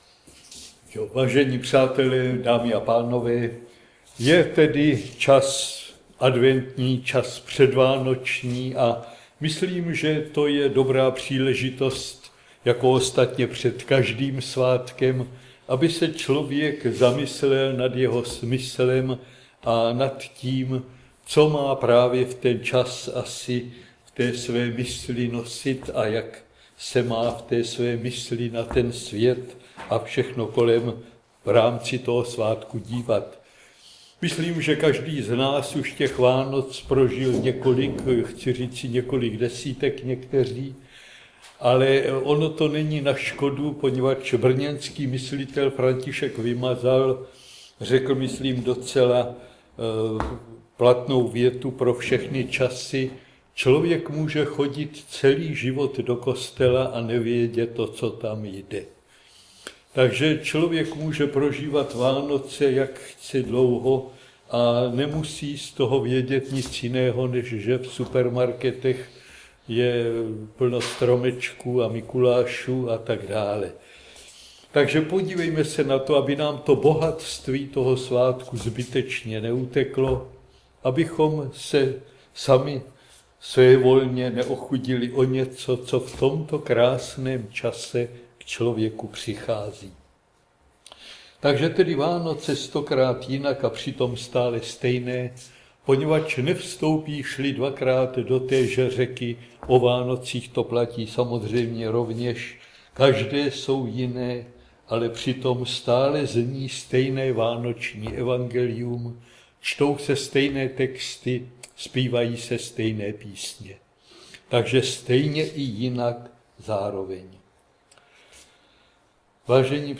Záznam přednášky